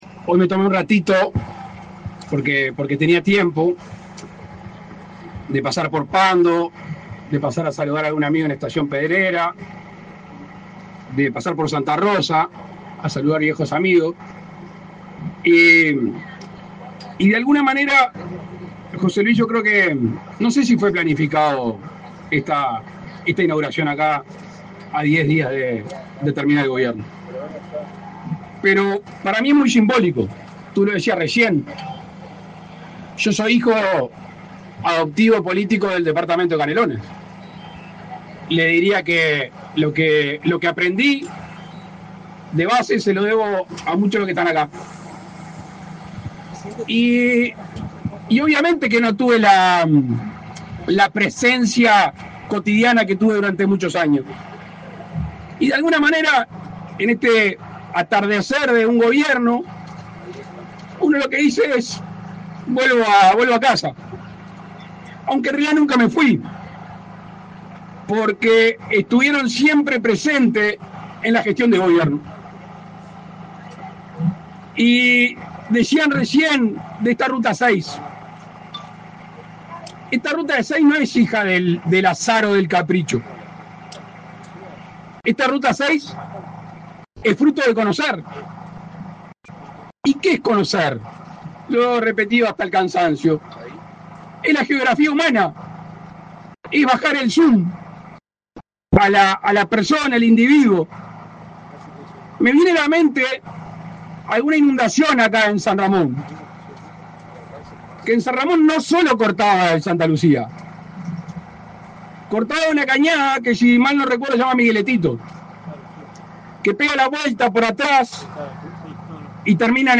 Palabras del presidente de la República, Luis Lacalle Pou
El presidente de la República, Luis Lacalle Pou, participó, este 19 de febrero, en la inauguración de obras de rehabilitación de 78 kilómetros de la